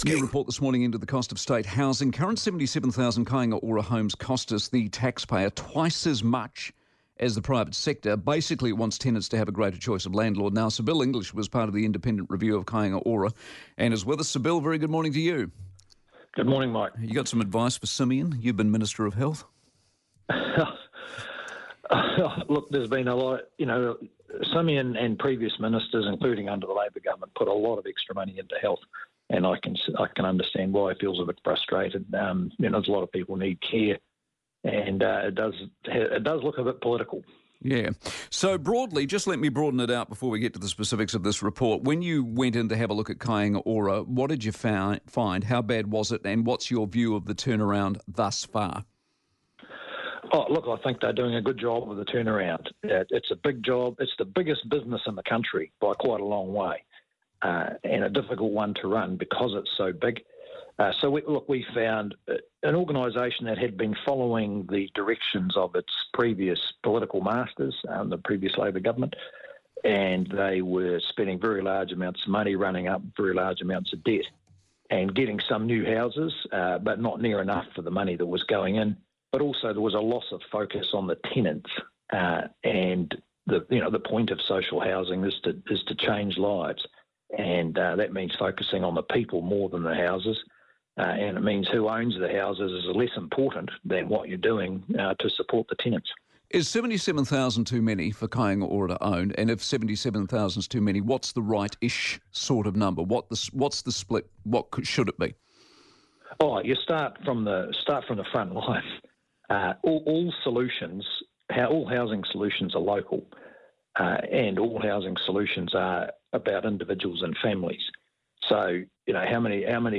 Newstalk ZB: Mike Hosking discusses our new report on Kāinga Ora's costs with Sir Bill English
Mike Hosking discussedour new report on Newstalk ZB with Rt Hon Sir Bill English, who contributed the foreword to the report, which finds that Kāinga Ora's 77,000 homes cost taxpayers twice as much as the private sector and advocates for greater tenant choice of landlord.